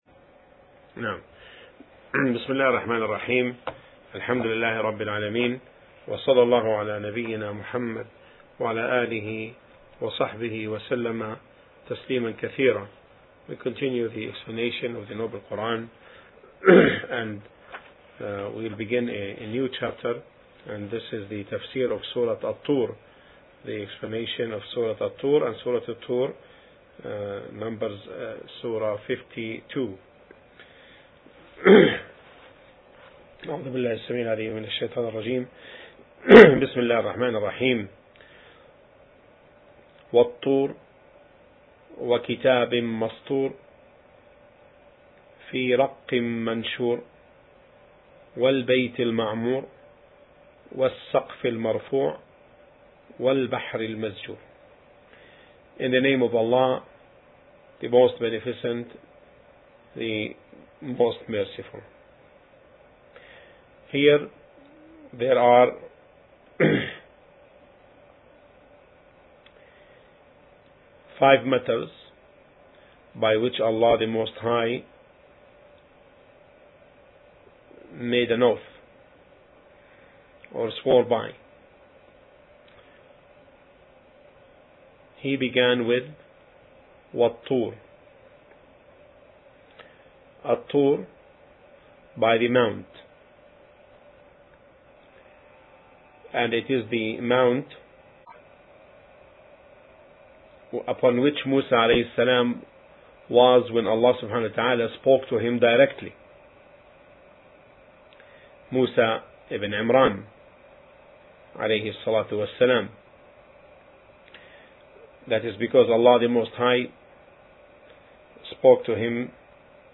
Category: TAFSIR